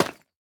Minecraft Version Minecraft Version latest Latest Release | Latest Snapshot latest / assets / minecraft / sounds / block / tuff_bricks / place4.ogg Compare With Compare With Latest Release | Latest Snapshot